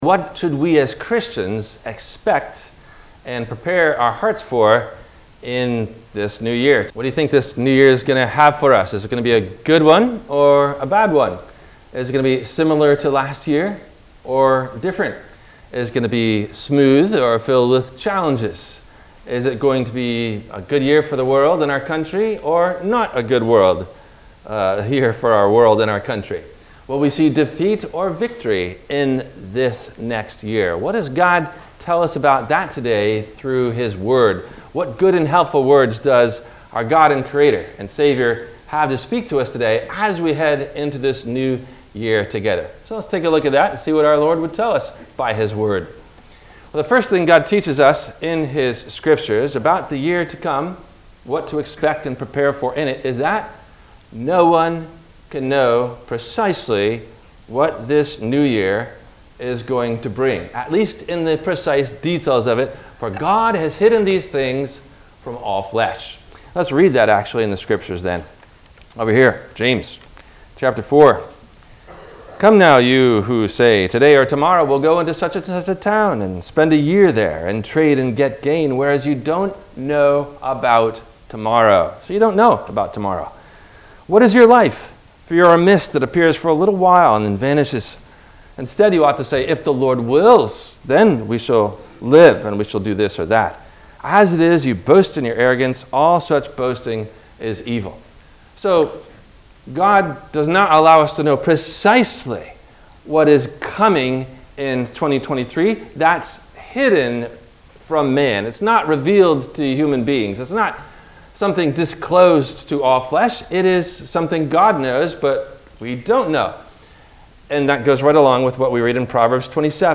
What To Expect and Prepare For In The New Year – WMIE Radio Sermon – January 16 2023